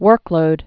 (wûrklōd)